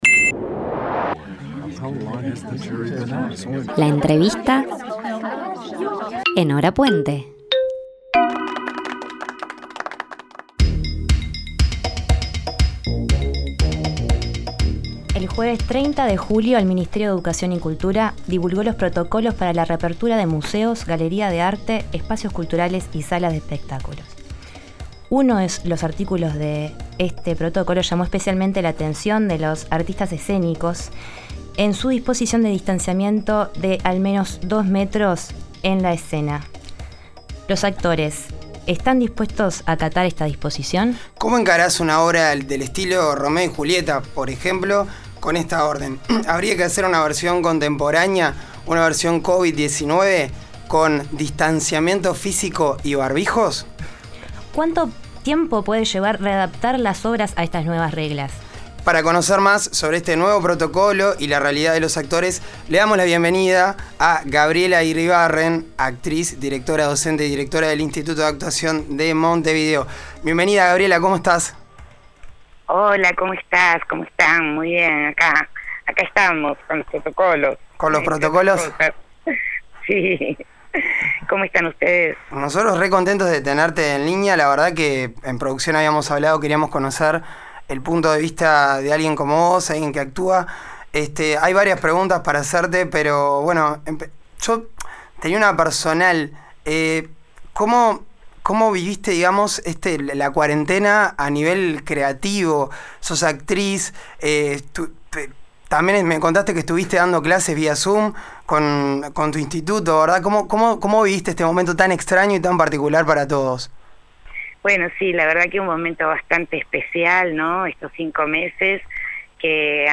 En la Entrevista Central de nuestro segundo programa nos contactamos con la actriz